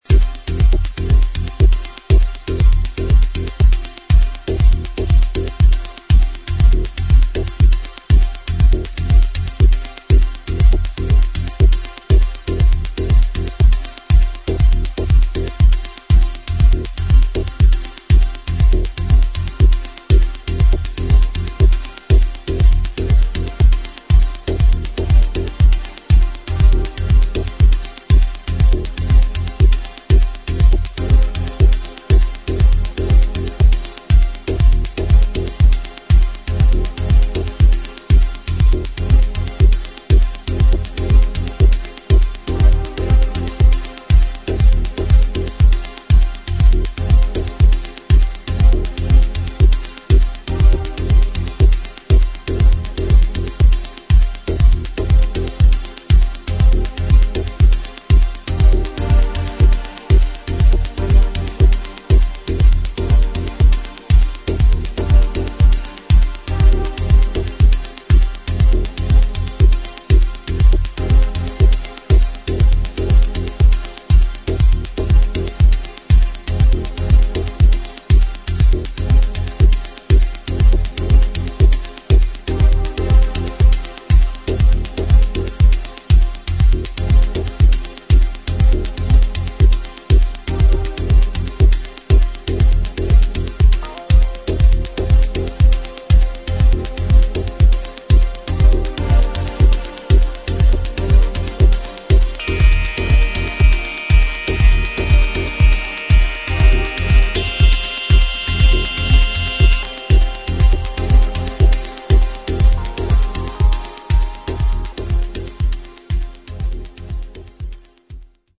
[ TECHNO / DETROIT / CLASSIC / EXPERIMENTAL ]